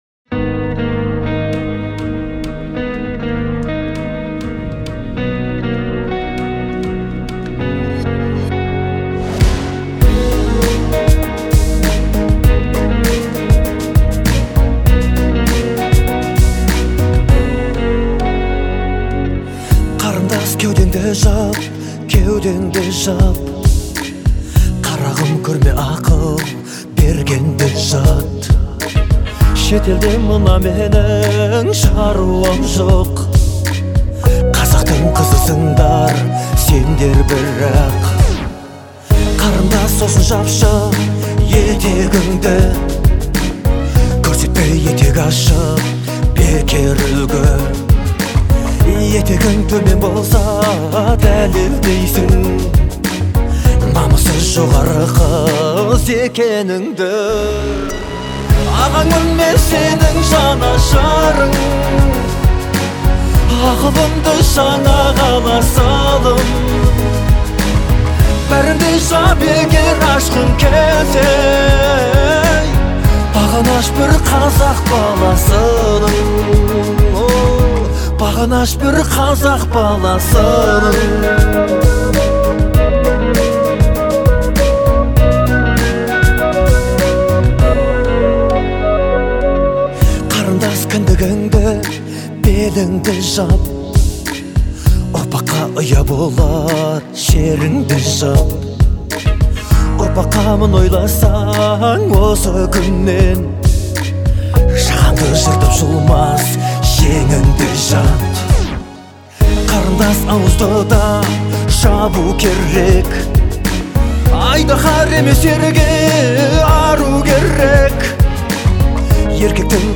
казахской поп-музыки
наполнена теплотой и ностальгией